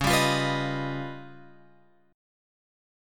C# 9th Flat 5th